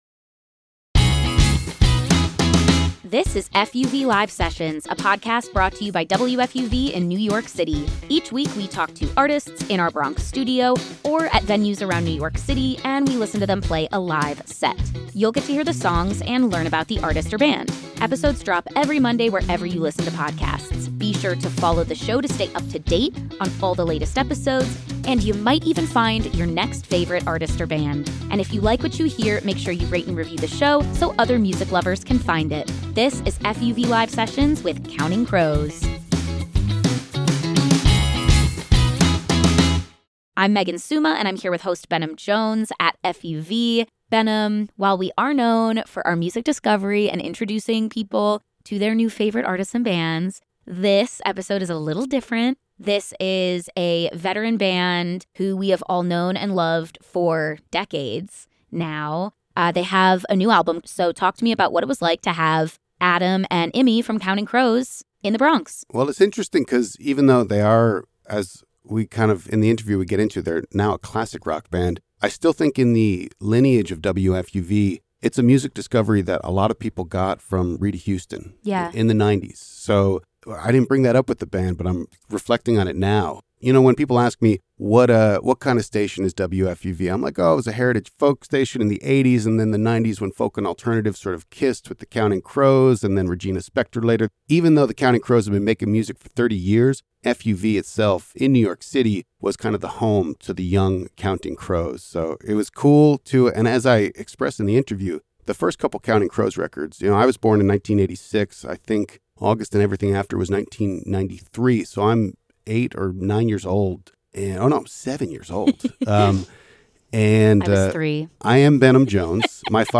in Studio-A for an exclusive duo performance